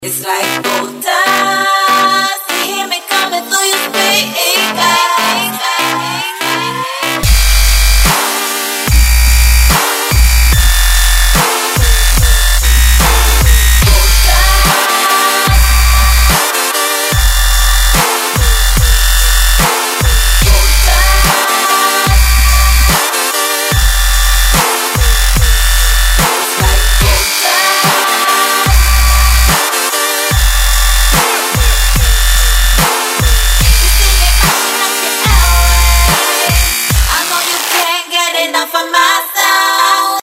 • Качество: 128, Stereo
Современный дабчик.